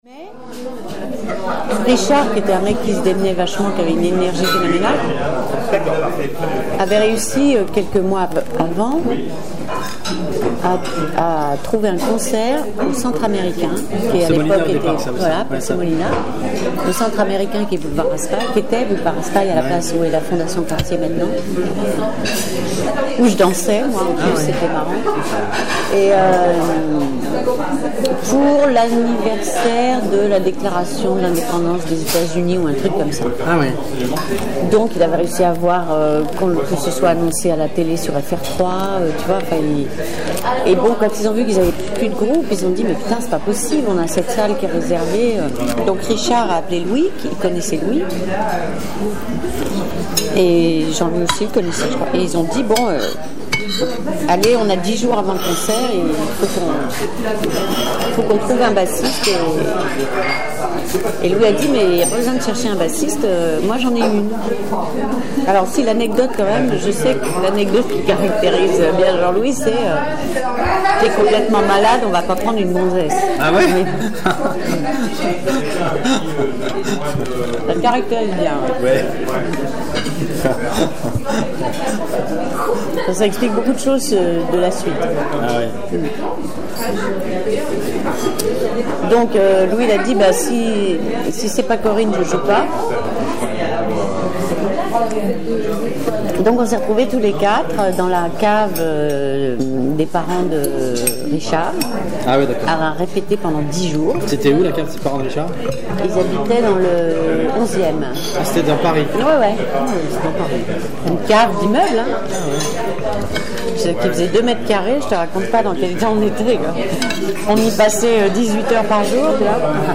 Extraits d'interviews de Corine menées pour la biographie de Téléphone...